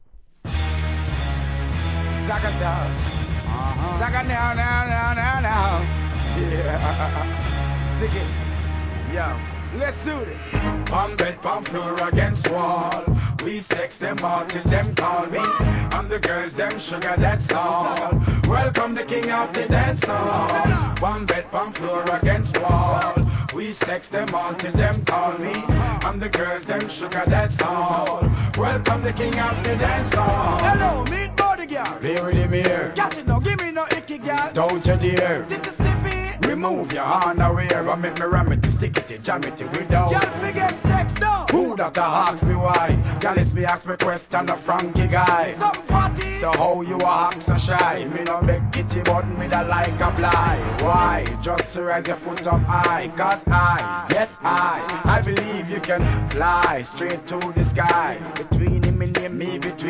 HIP HOP x REGGAEブレンド